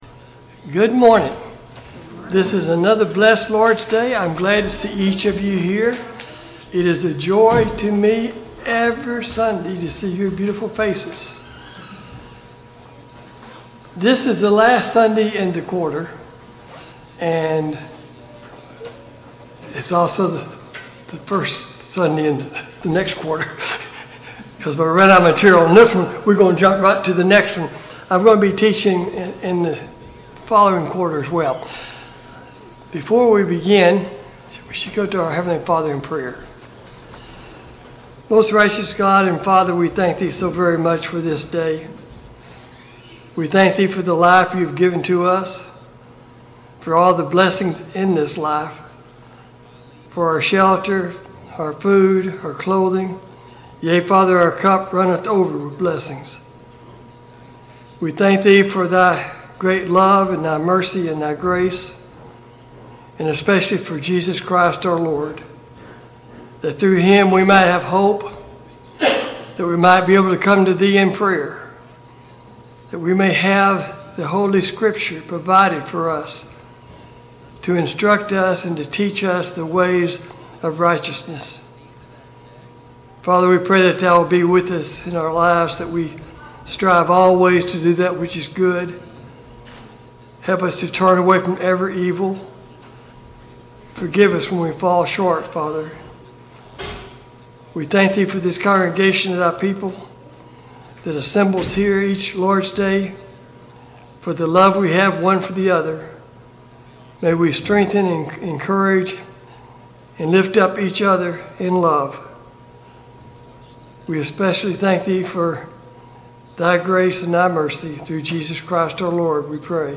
Service Type: Sunday Morning Bible Class Topics: Introduction to Mark